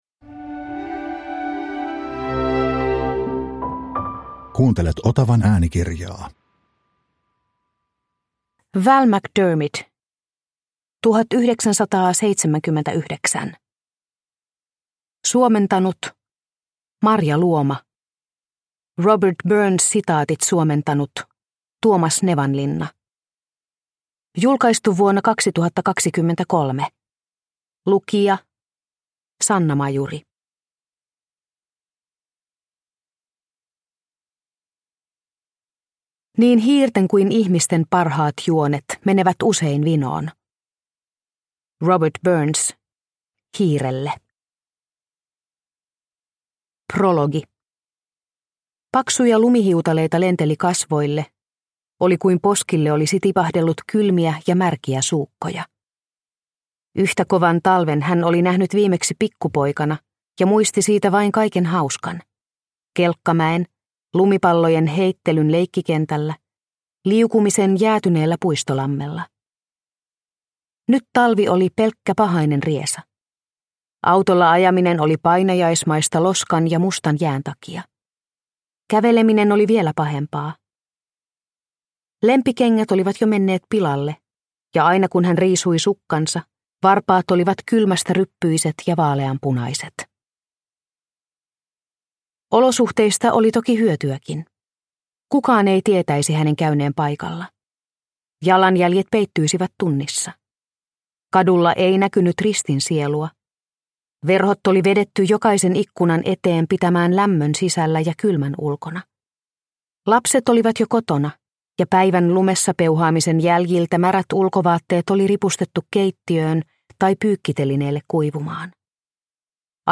1979 – Ljudbok – Laddas ner